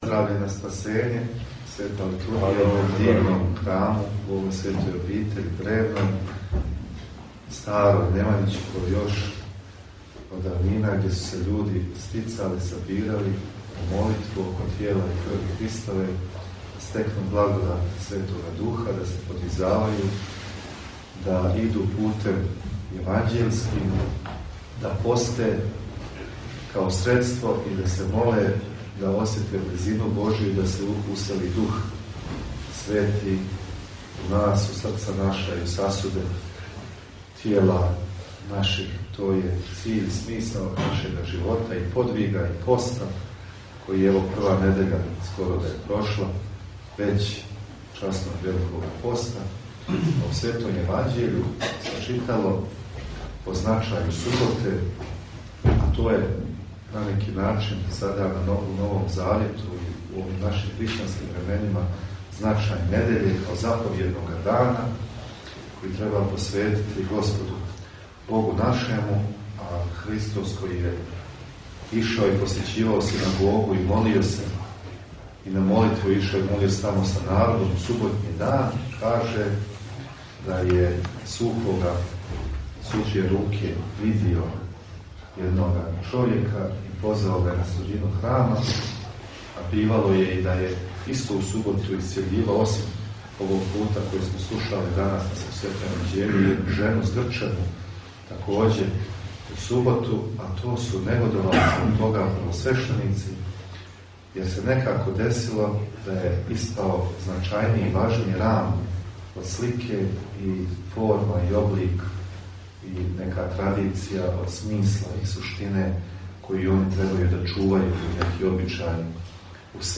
Ime: 04.03.2023-besjeda Vladike Metodija na Teodorovu subotu; Opis: Besjeda Vladike Metodija na Teodorovu subotu u manastiru Uroševica kod Berana Tip: audio/mpeg
Besjeda Njegovog Preosveštenstva Episkopa budimljansko-nikšićkog G. Metodija izgovorena na Svetoj Arhijerejskoj Liturgiji koju je na Teodorovu subotu, 4. marta 2023.